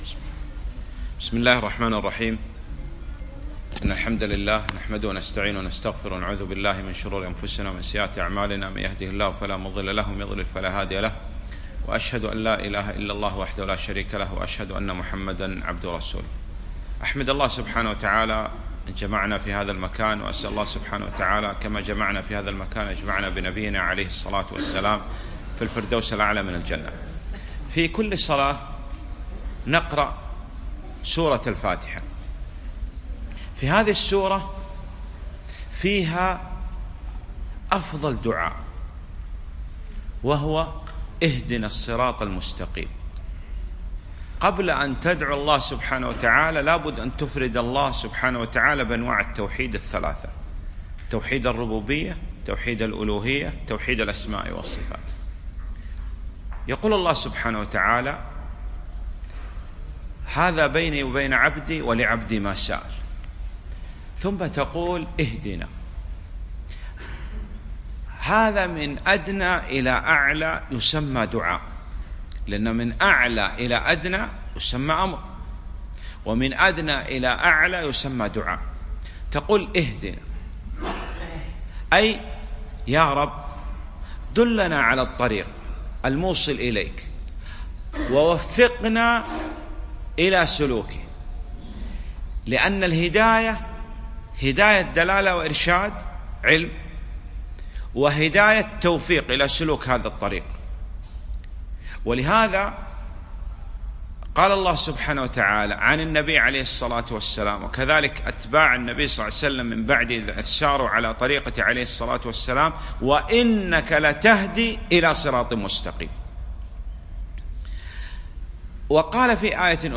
محاضرة - الصراط المستقيم